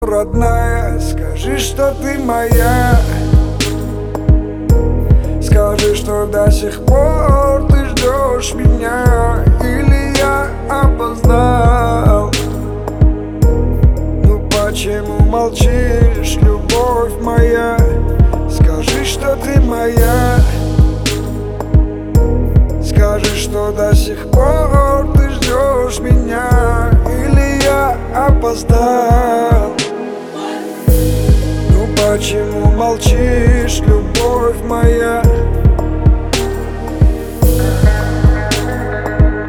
• Качество: 320, Stereo
лирика
грустные
русский рэп